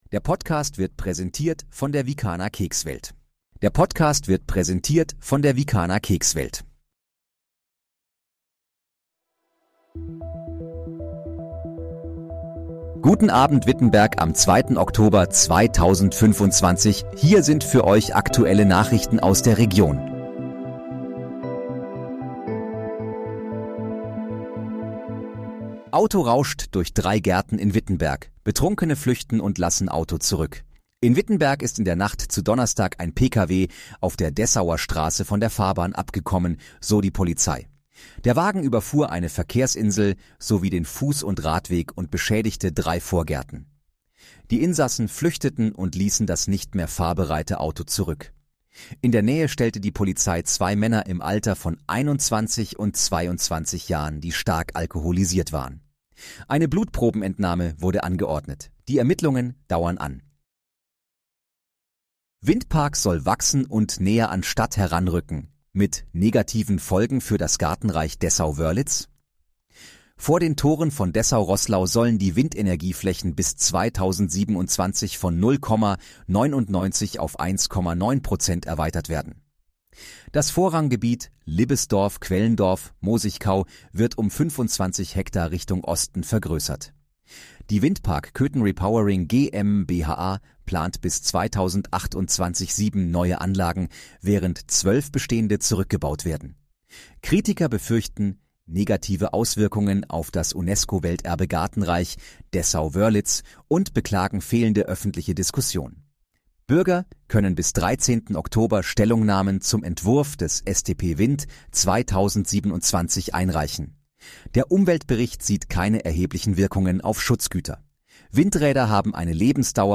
Guten Abend, Wittenberg: Aktuelle Nachrichten vom 02.10.2025, erstellt mit KI-Unterstützung
Nachrichten